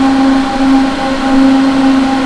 Index of /~quake2/baseq2/sound/cromavp2/ambients